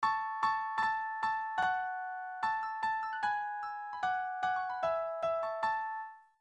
or certain chord progressions, such as the following:—
Harmonic Leit-Motif. (Grail Theme, “Lohengrin.”)